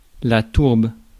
Ääntäminen
Synonyymit sphaigneraie molineraie Ääntäminen France Tuntematon aksentti: IPA: /tuʁb/ Haettu sana löytyi näillä lähdekielillä: ranska Käännös Substantiivit 1. торф {m} (torf) Suku: f .